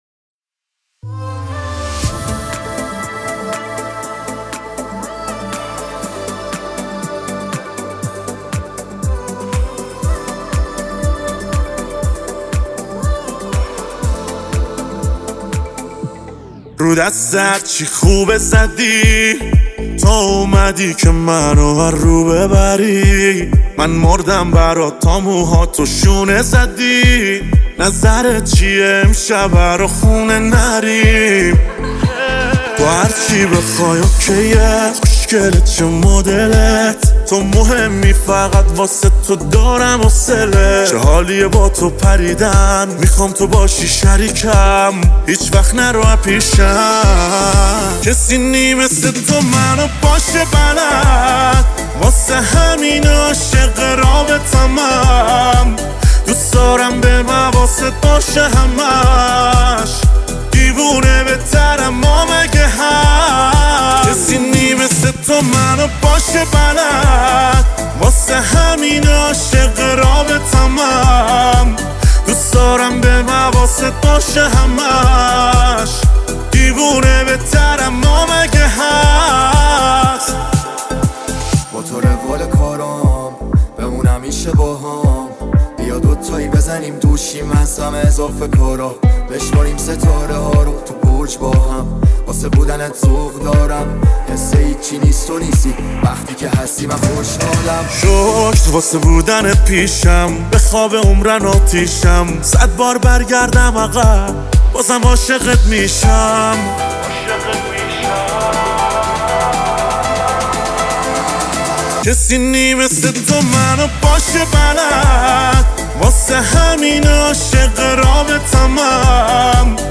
موزیک بسیار بسیار عاشقانه و جذاب